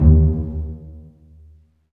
Index of /90_sSampleCDs/Roland LCDP13 String Sections/STR_Vcs Marc&Piz/STR_Vcs Pz.2 amb
STR PIZZ.02L.wav